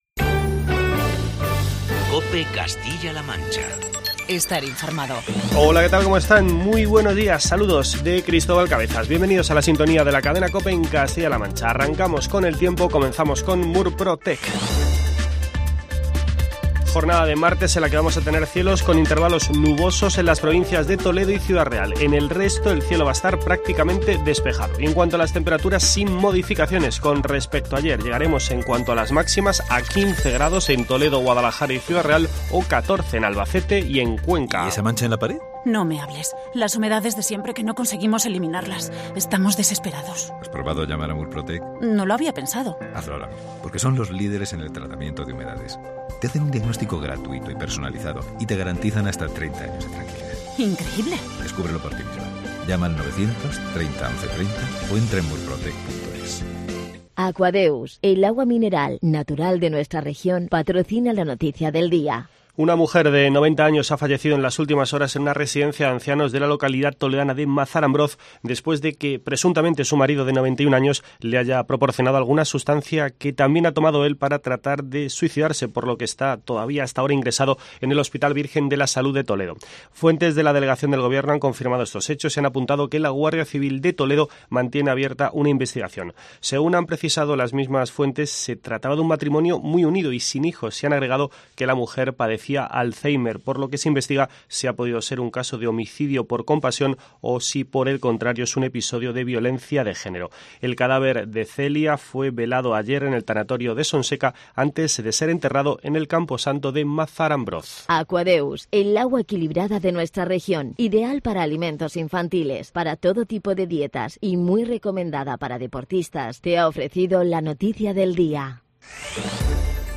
informativo matinal de COPE Castilla-La Mancha.